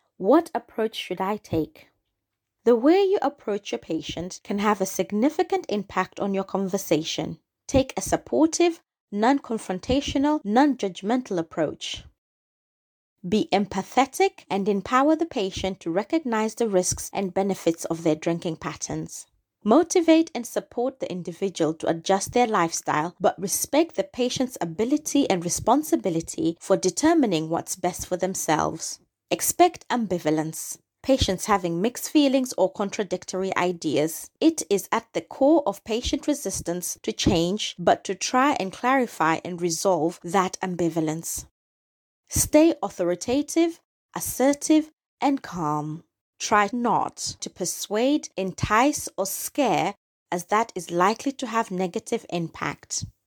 A nurse in the emergency department provides advice to patient on drinking responsibly.